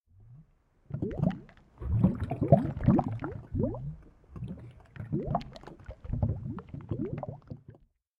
lava.mp3